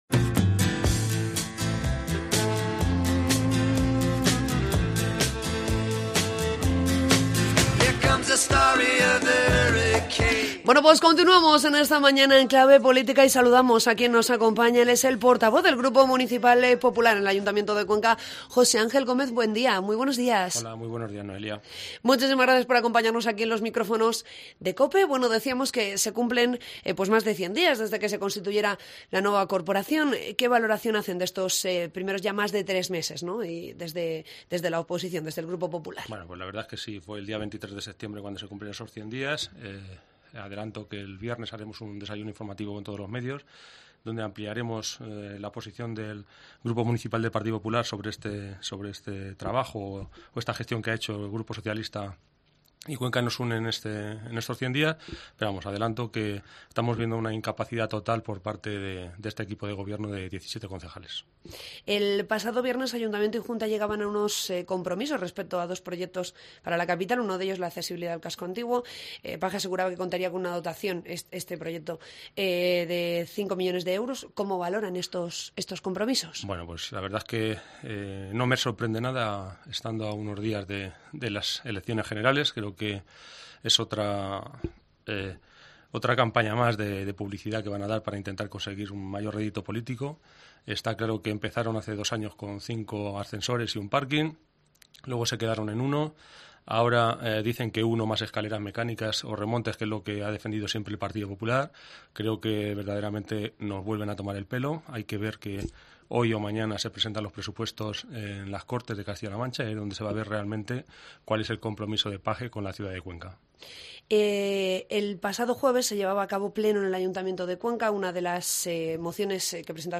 Entrevista con el portavoz del Grupo Municipal Popular, José Ángel Gómez Buendía